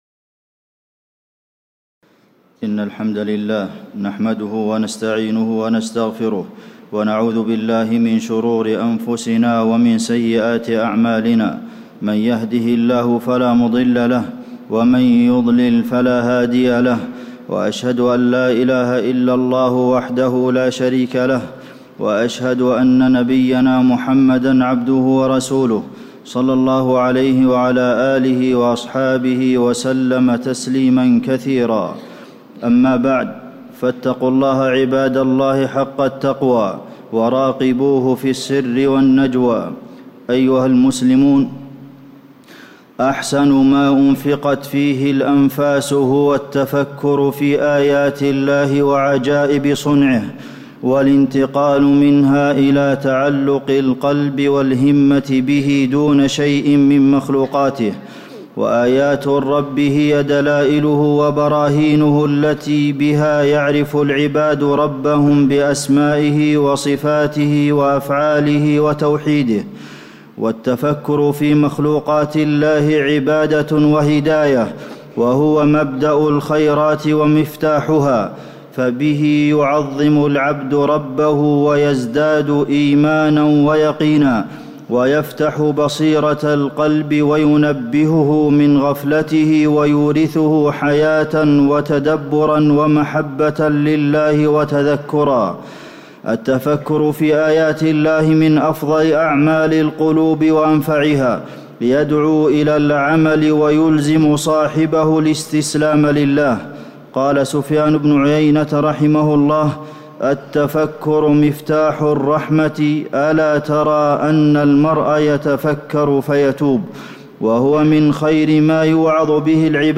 تاريخ النشر ١٧ شوال ١٤٣٧ هـ المكان: المسجد النبوي الشيخ: فضيلة الشيخ د. عبدالمحسن بن محمد القاسم فضيلة الشيخ د. عبدالمحسن بن محمد القاسم التفكر في آيات الله